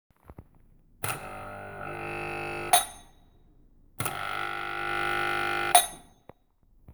Achja, unsere Klingel. Die ist nach wir vor kaputt. Sie klingt so:
Der Klang an sich wäre nicht das Problem. Sie ist nur sehr leise.